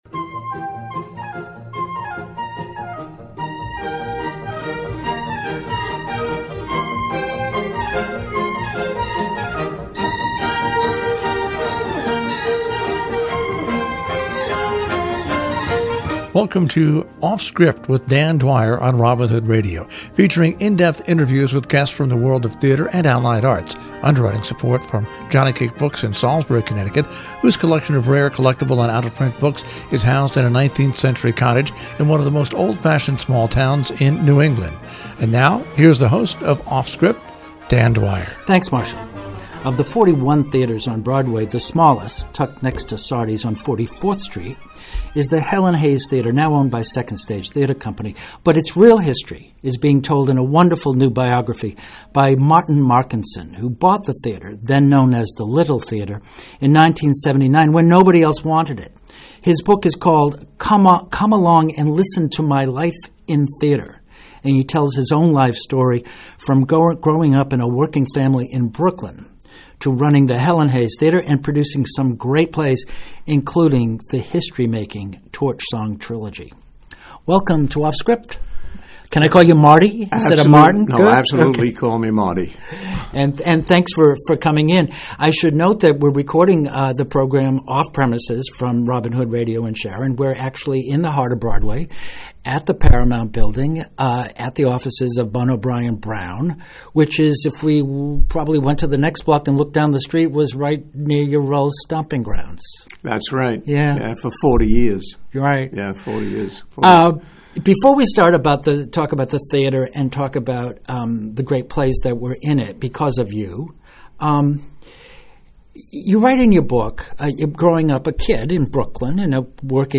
A rich memoir by a former Broadway theatre owner and producer who relays in his intimate, easy voice his journey from an outsider to a self-made successful player in a most competitive, but little understood business.